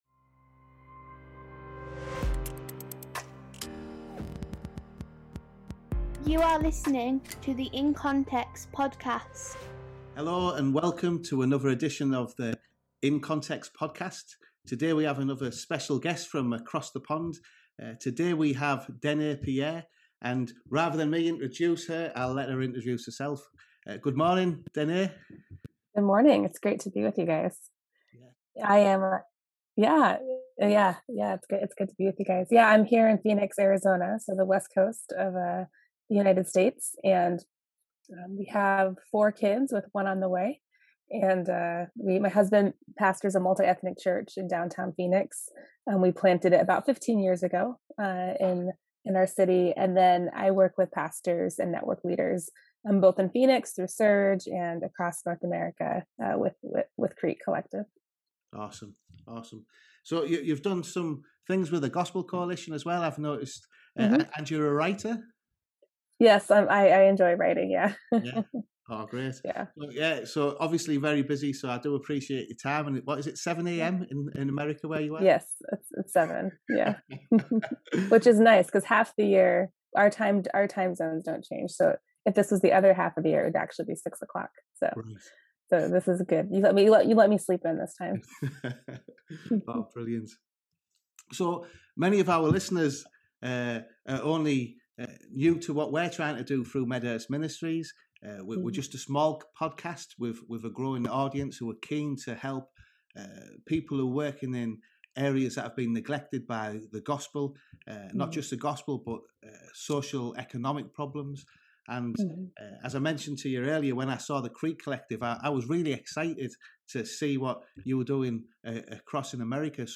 Download - 75: #71: Navigating Cultural Change. An interview